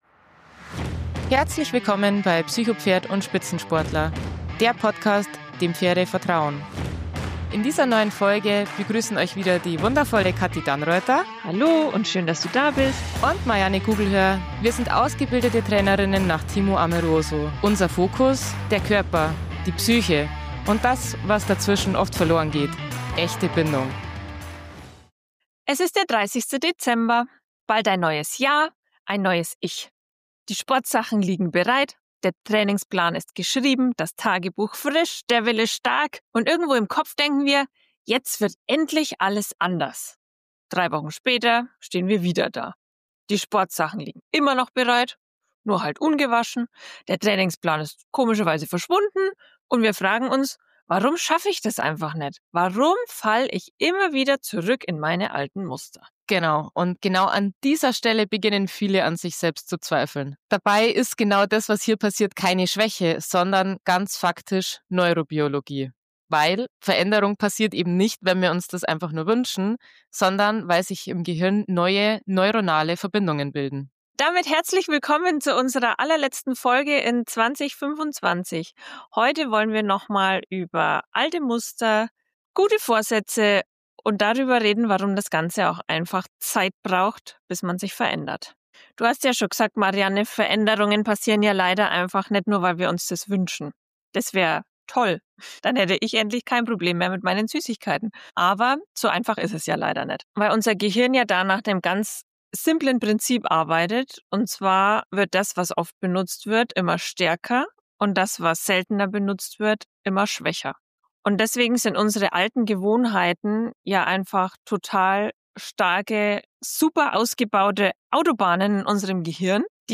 In dieser letzten Folge des Jahres teilen wir persönliche Gedanken, eigene Ziele und sprechen darüber, wie hilfreich es sein kann, sich kleine, realistische Zwischenziele zu setzen – statt sich von großen Vorsätzen unter Druck setzen zu lassen. Eine ruhige, ehrliche Silvesterfolge zum Innehalten, Umdenken und bewussten Ausrichten – für alle, die nicht lauter, sondern klarer ins neue Jahr starten wollen.